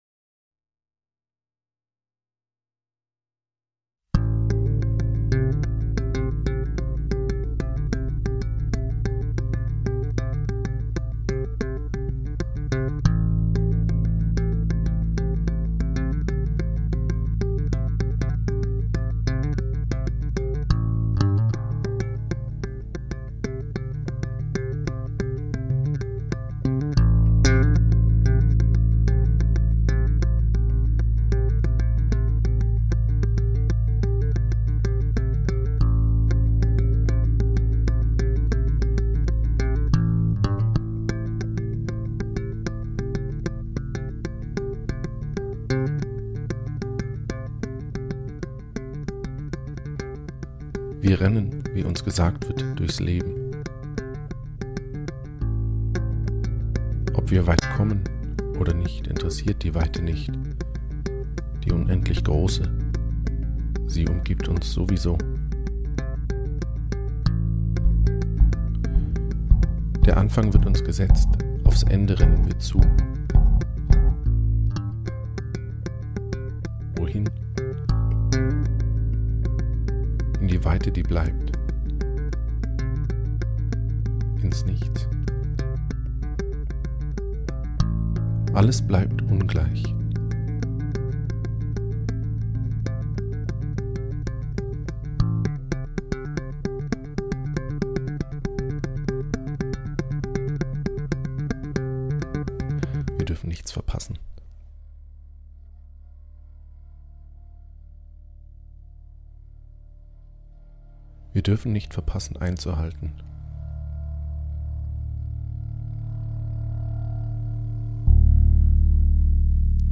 tonversion für stimme und e-bass: